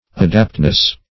Adaptness \A*dapt"ness\, n.